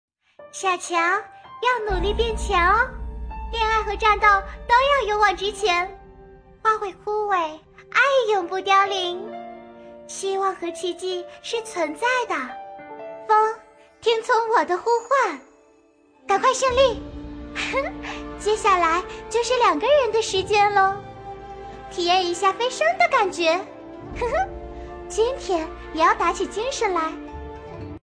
【女13号游戏角色】小乔
【女13号游戏角色】小乔.mp3